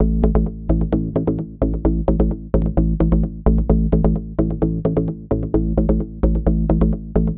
Mid Bass Loop.wav